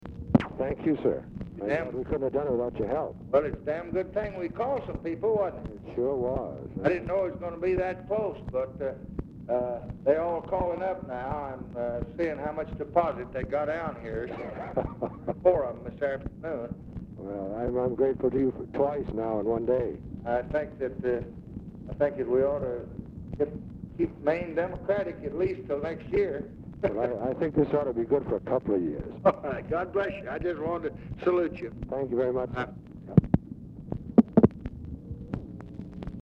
Telephone conversation
RECORDING STARTS AFTER CONVERSATION HAS BEGUN
Format Dictation belt
Oval Office or unknown location